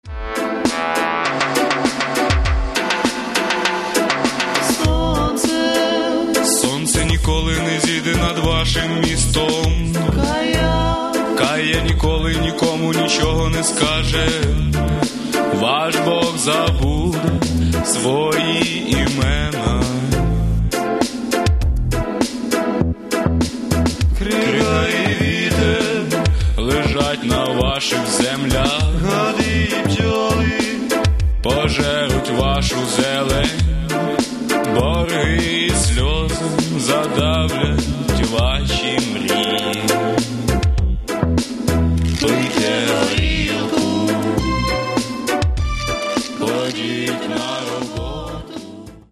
Каталог -> Рок и альтернатива -> Регги